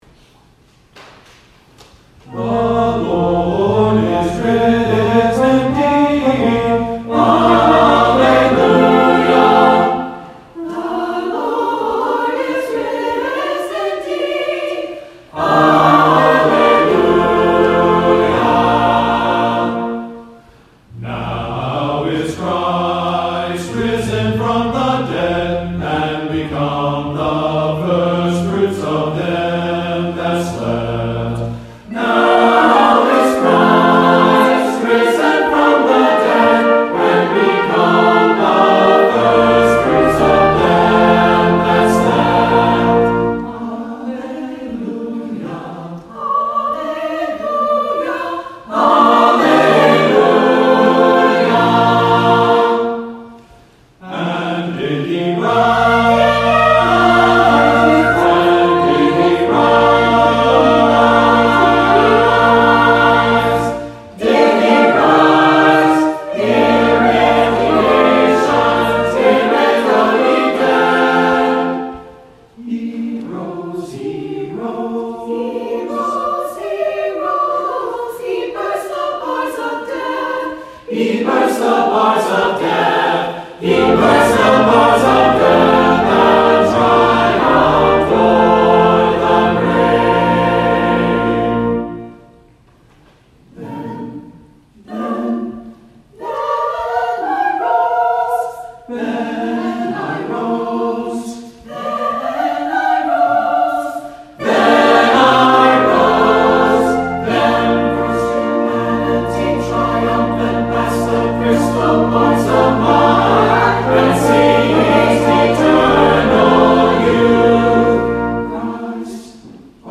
Easter Anthem
Call to Worship: Trinity Chancel Choir
Easter Anthem.mp3